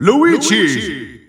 Announcer pronouncing Luigi's name in Dutch.
Luigi_Dutch_Announcer_SSBU.wav